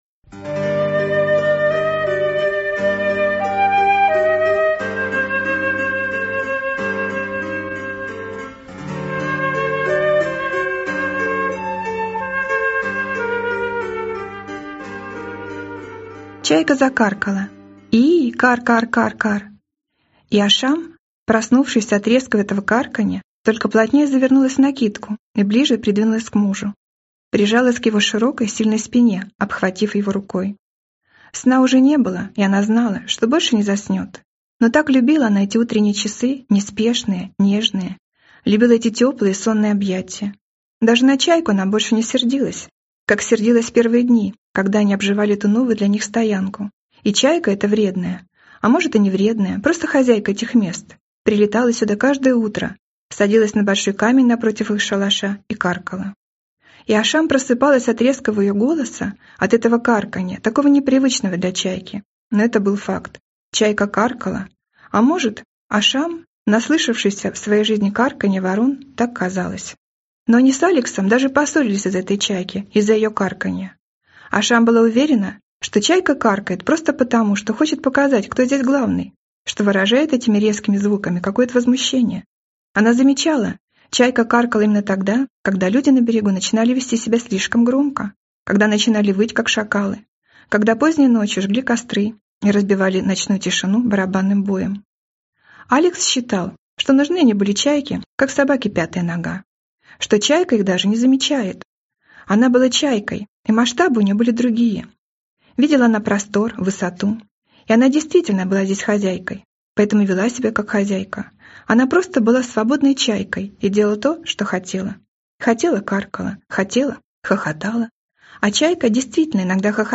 Aудиокнига Как хорошо быть голой и свободной